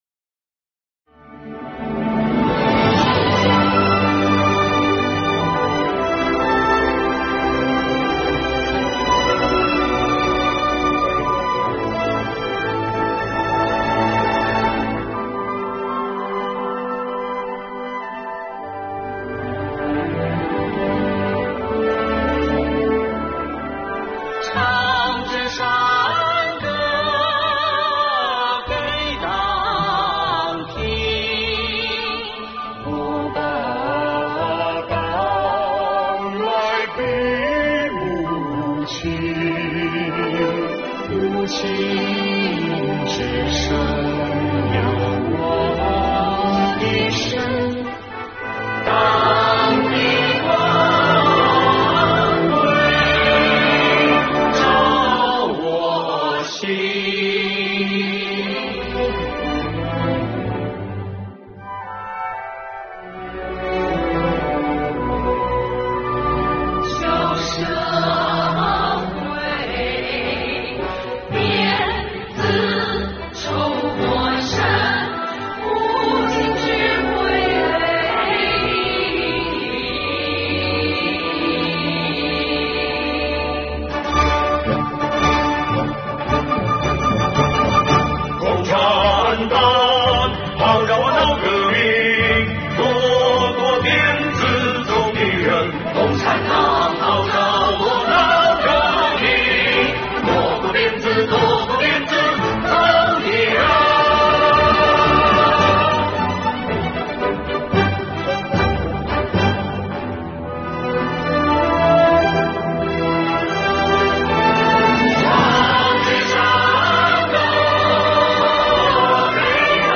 音乐响起，挥舞的党旗构成了一幅美丽画面，铿锵的歌词带领大家重温百年峥嵘岁月，婉转悠扬的歌声唱出了大同税务人的风采，唱出了对党和祖国深深热爱之情。